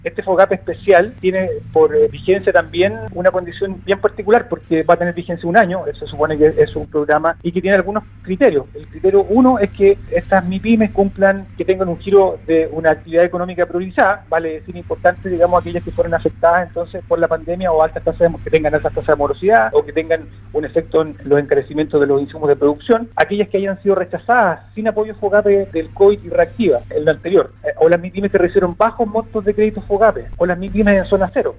La idea es establecer un nuevo Fogape focalizado en quienes no obtuvieron dicho crédito en sus anteriores versiones, principalmente micros, pequeñas y medianas empresas, las que podrán acceder a un determinado porcentaje del capital de los créditos, administrados por el Banco Estado y supervisado por la Comisión para el Mercado Financiero. El seremi de Economía en Los Lagos, Luís Cárdenas, expuso en entrevista con Radio Sago, que tendrán prioridad aquellas Mipymes más afectadas por la pandemia y tendría una duración de un año.